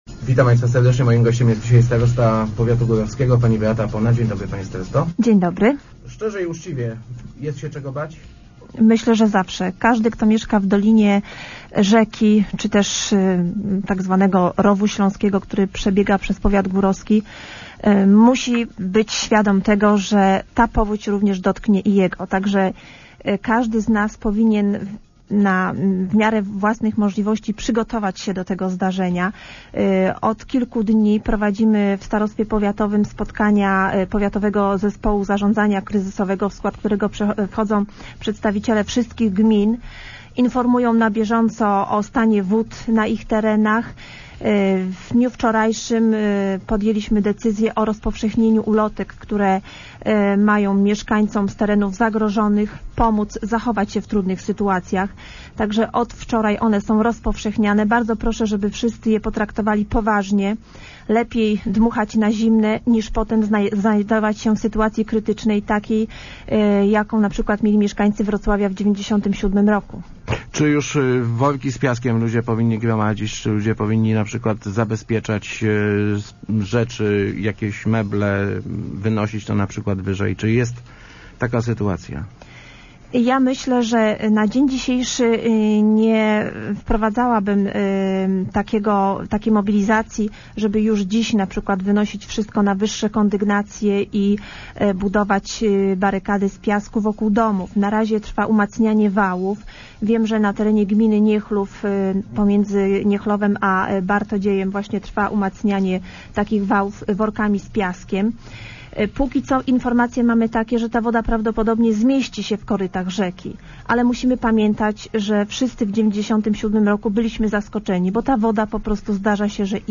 Przygotowujemy si� do przyj�cia kulminacyjnej fali powodziowej – mówi�a w Rozmowach Elki Beata Pona, starosta powiatu górowskiego. Na razie, jak zapewnia, nie ma zagro�enia, ale nale�y by� przygotowanym na wylanie Odry.